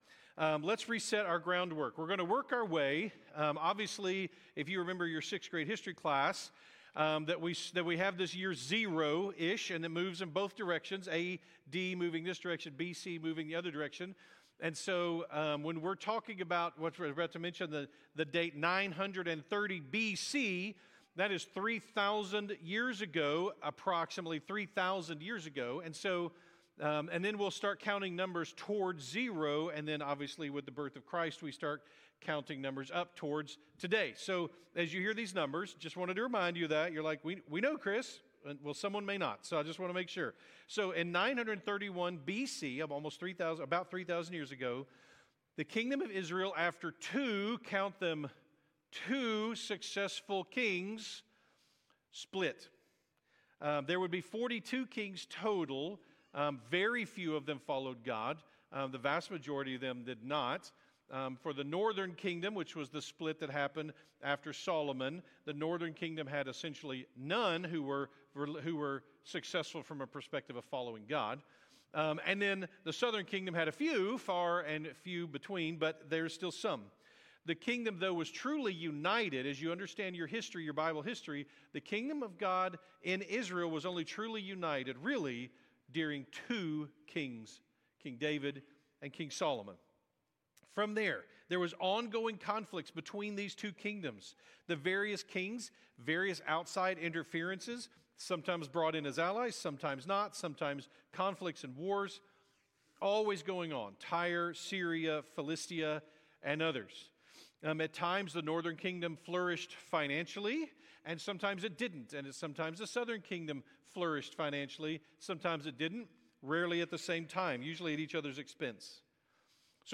October-5th-2025-Sunday-Morning.mp3